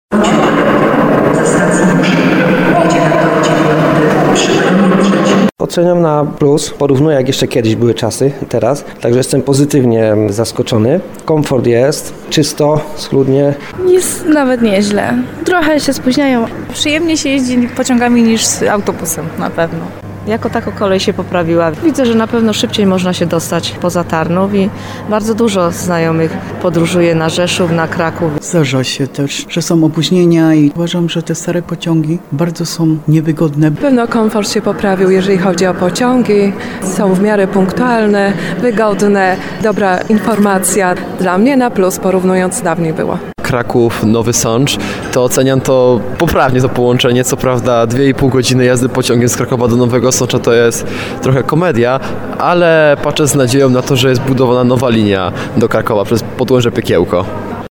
Podróżni, z którymi rozmawialiśmy na tarnowskim dworcu PKP, przyznają że komfort jazdy pociągami na przestrzeni ostatnich lat znacznie się poprawił. Problemem pozostaje ciągle zbyt długi czas przejazdu na wielu odcinkach.